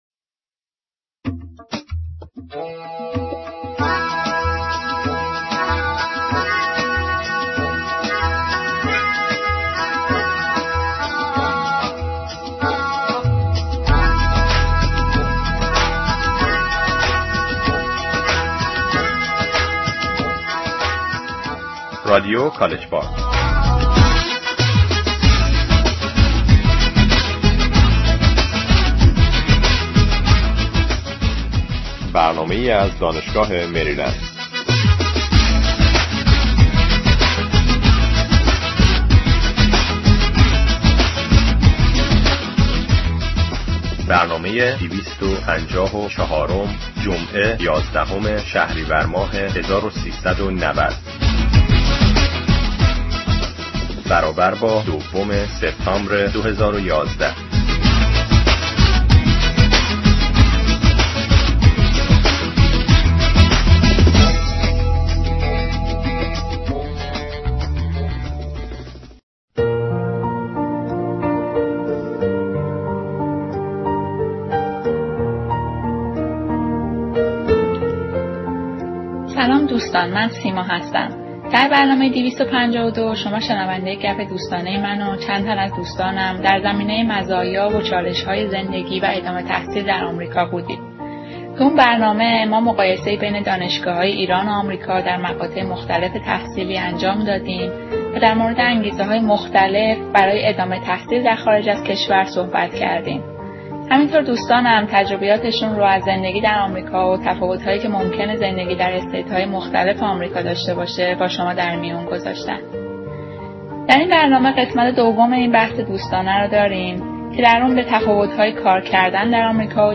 میزگرد (بخش اول): مزایا و چالشهای ادامه تحصیل در خارج از کشور (آمریکا)